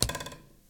throwing_arrow_hit_wall.ogg